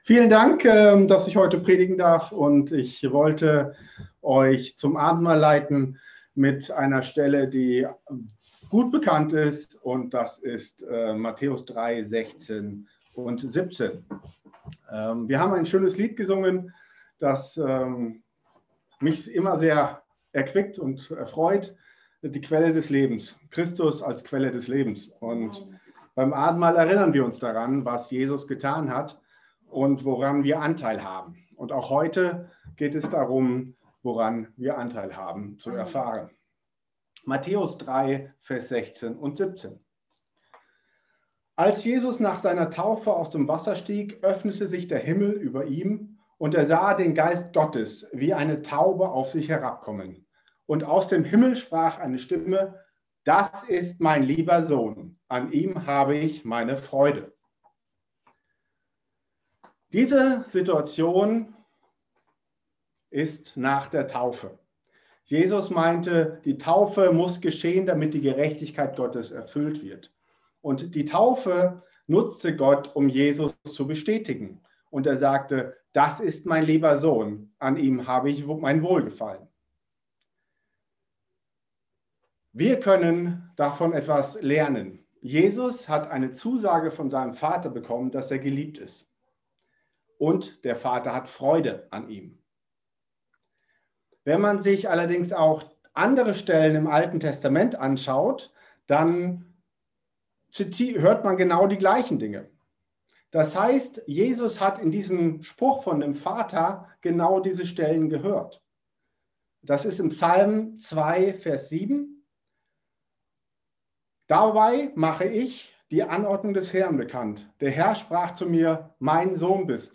Sonntagspredigt